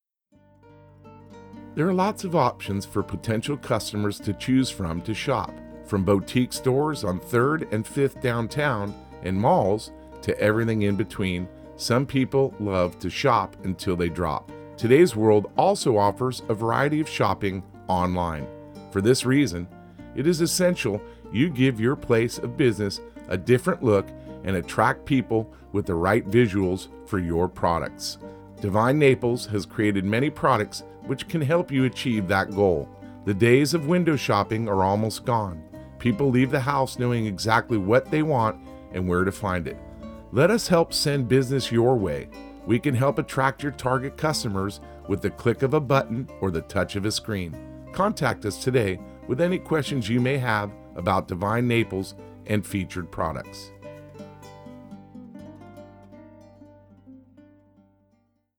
local_business_landing_shopping_text_and_music_.mp3